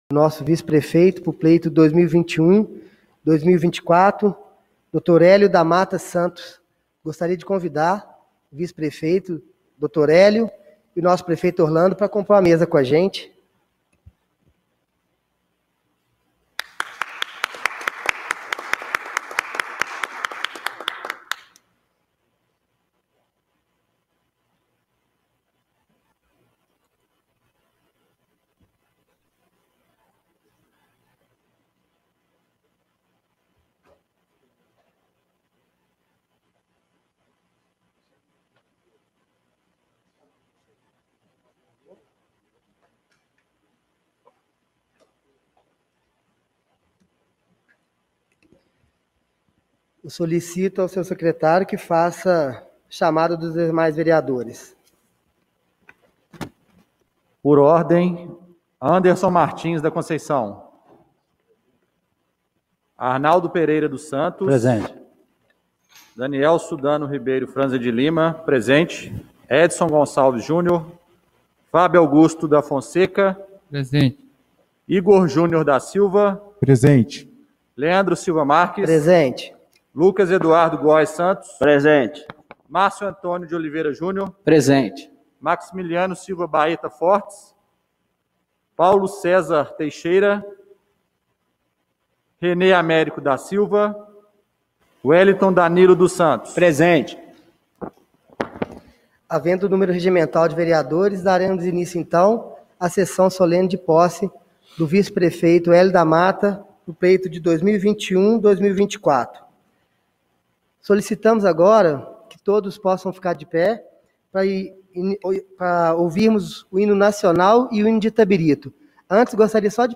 Reunião de Posse do Vice-Prefeito do dia 06/01/2021